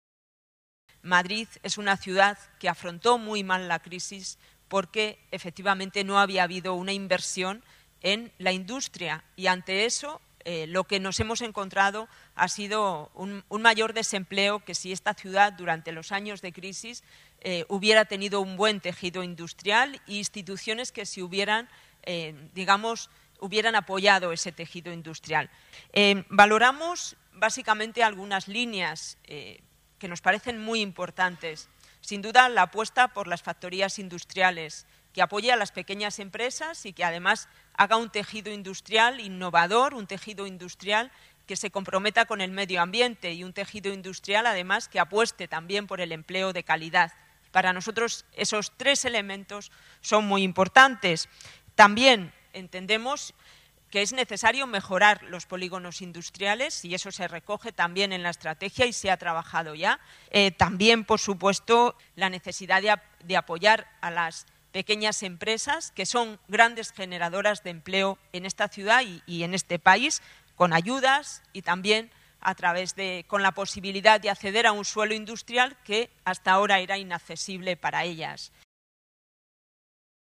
Presentación estrategia
Purificación Causapié habla sobre la falta de industria en Madrid